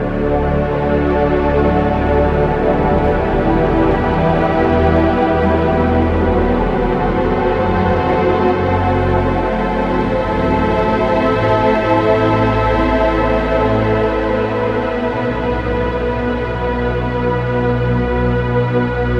Acp Slow And Epic Pad 01
描述：在Nexus vst的帮助下
标签： 100 bpm Orchestral Loops Pad Loops 3.25 MB wav Key : A
声道立体声